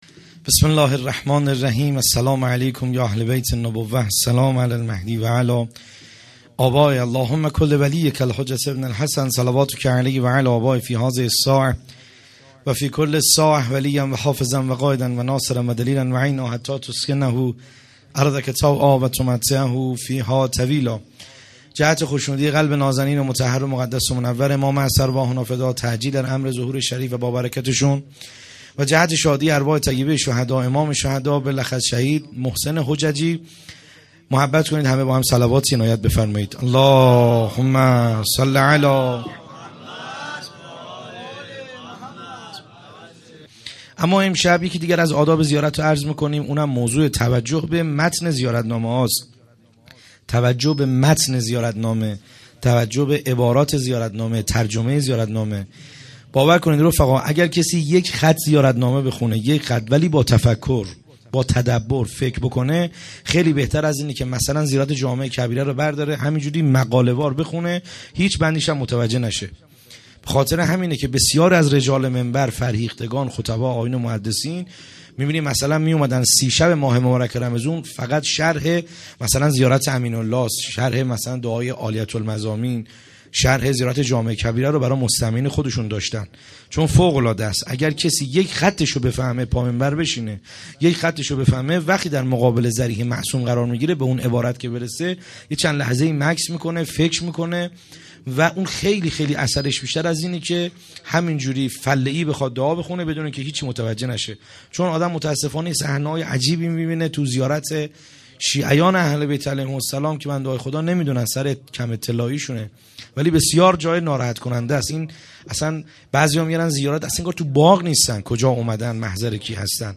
خیمه گاه - بیرق معظم محبین حضرت صاحب الزمان(عج) - سخنرانی